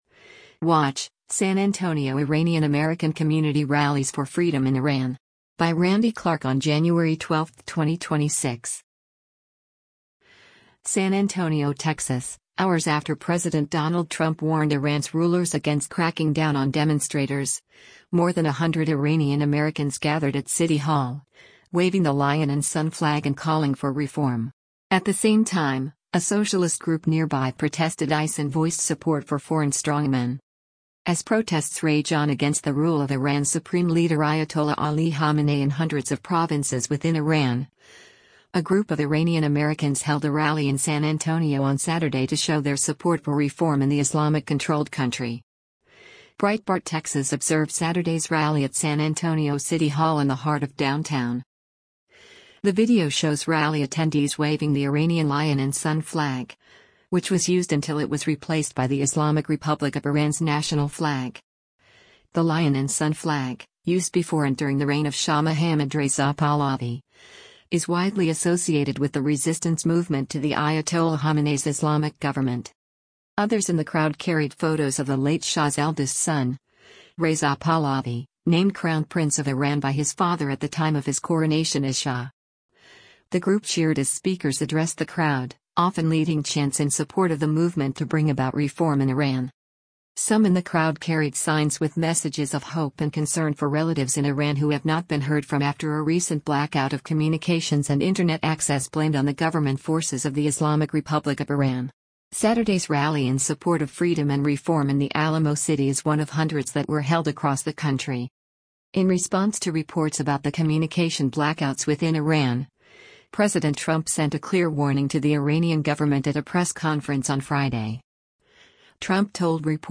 Breitbart Texas observed Saturday’s rally at San Antonio City Hall in the heart of downtown.
The group cheered as speakers addressed the crowd, often leading chants in support of the movement to bring about reform in Iran.